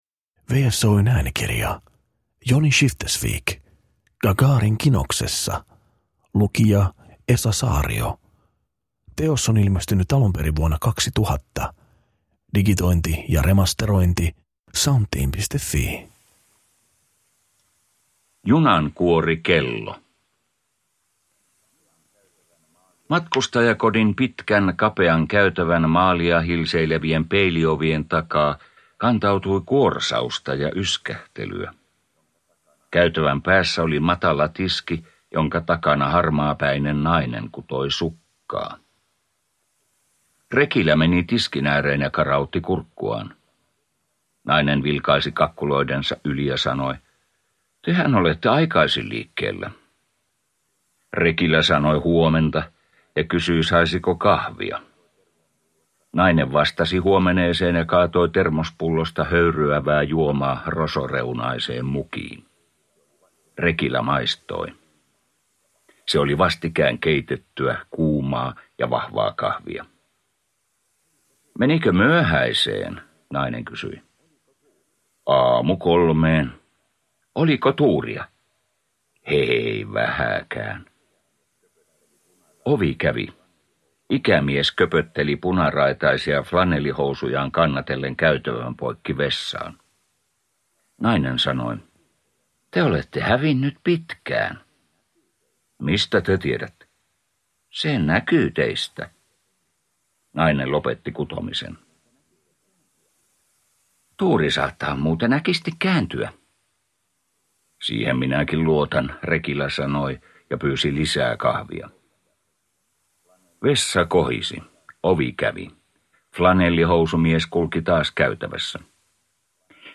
Gagarin kinoksessa – Ljudbok